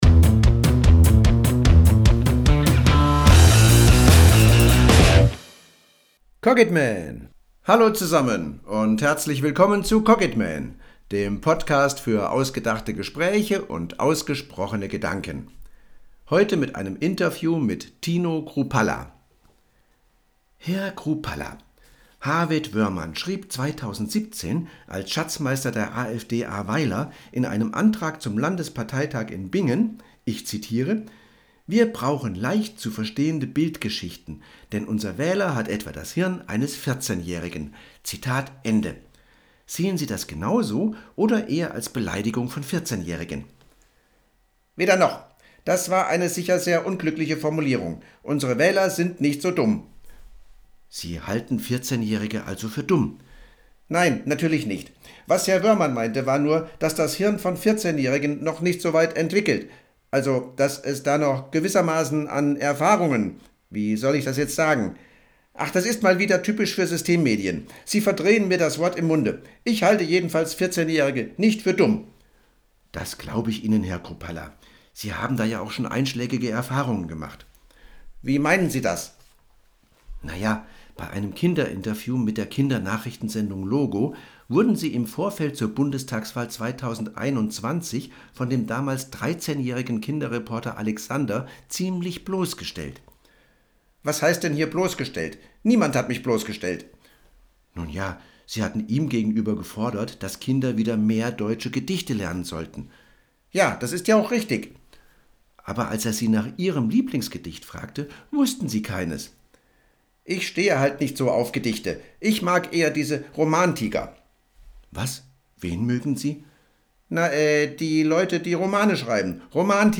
Dialog_Chrupalla.mp3